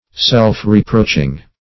Search Result for " self-reproaching" : The Collaborative International Dictionary of English v.0.48: Self-reproaching \Self`-re*proach"ing\, a. Reproaching one's self.